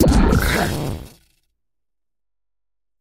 Cri d'Ampibidou dans Pokémon HOME.